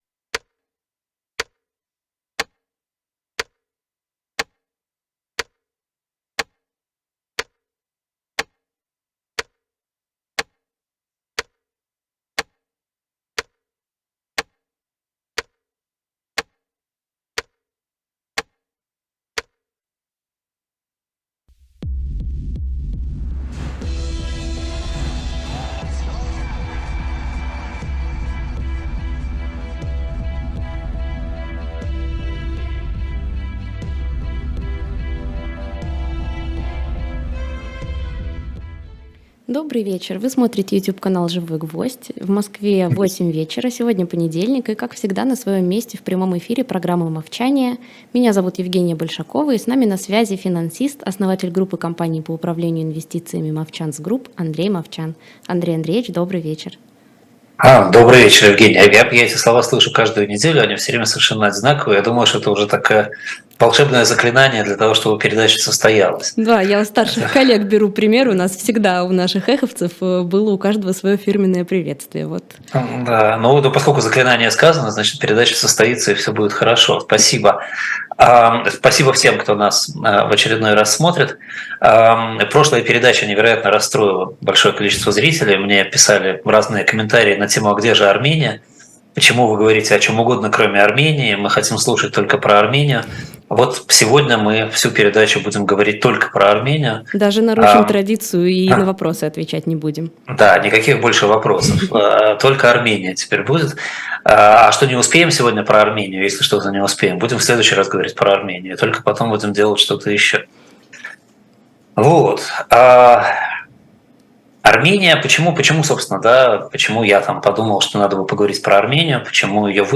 В эфире еженедельная программа с участием Андрея Мовчана